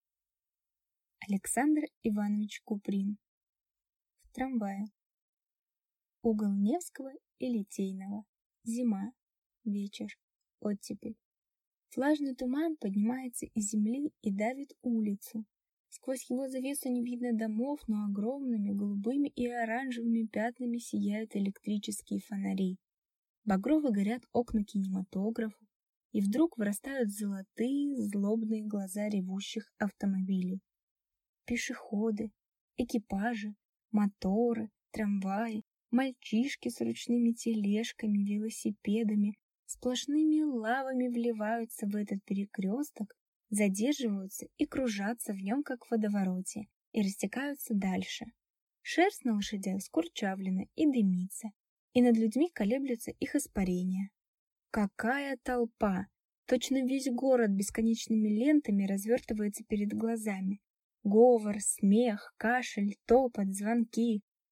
Аудиокнига В трамвае | Библиотека аудиокниг